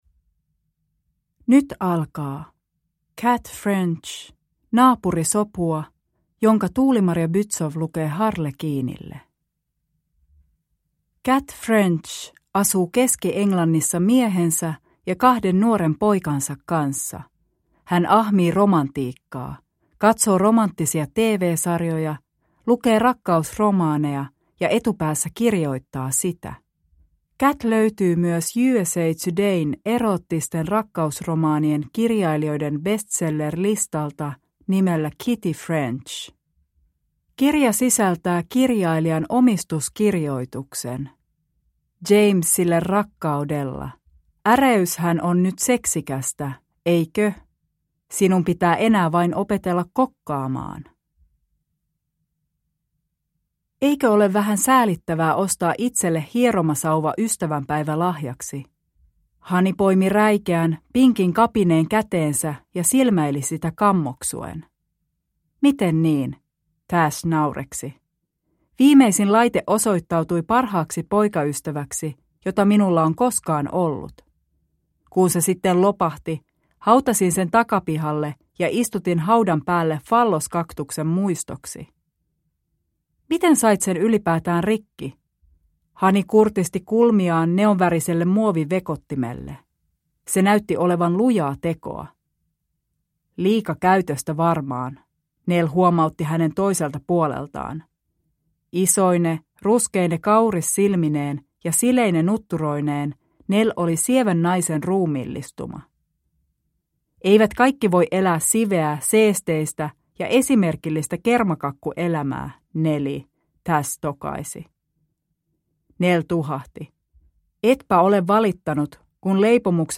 Naapurisopua (ljudbok) av Kat French